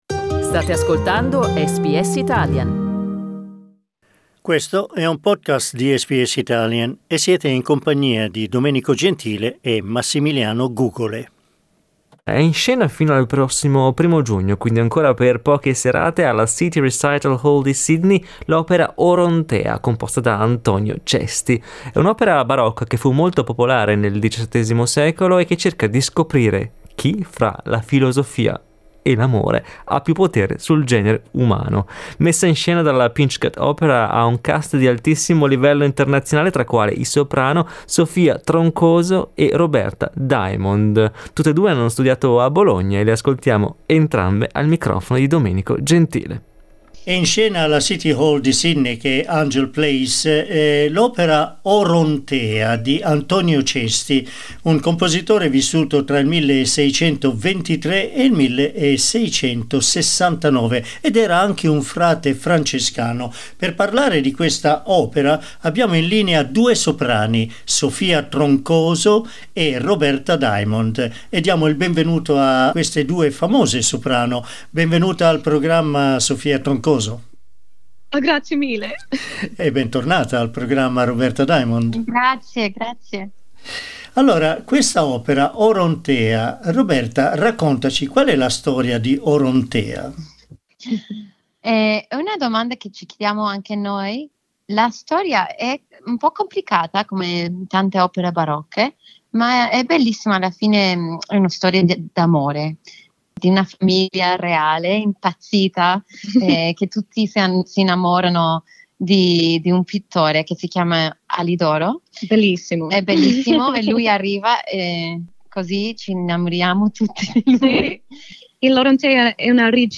Ascolta l'intervista: SKIP ADVERTISEMENT LISTEN TO Dopo oltre 350 anni "Orontea" di Cesti viene messa in scena anche in Australia SBS Italian 12:24 Italian Ascolta SBS Italian ogni giorno, dalle 8am alle 10am.